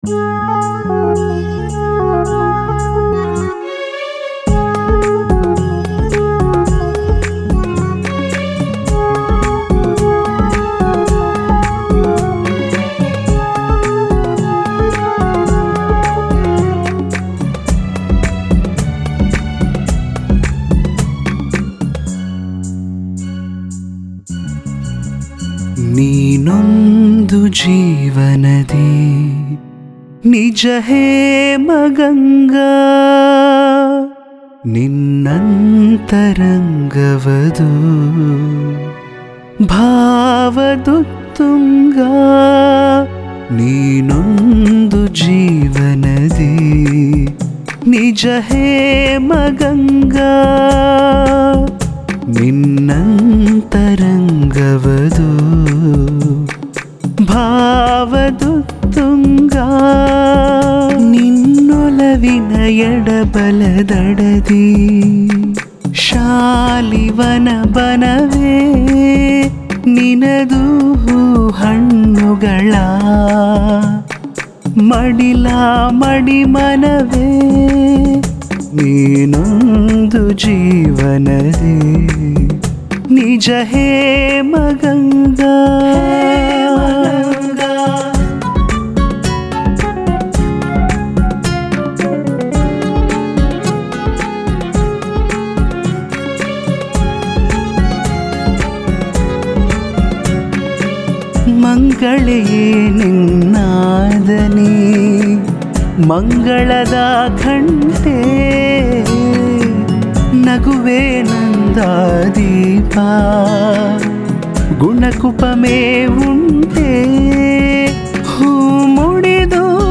ಯುವ ಗಾಯಕ
👆ರಾಗ ಸಂಯೋಜನೆ-ಗಾಯನ